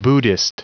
Prononciation du mot buddhist en anglais (fichier audio)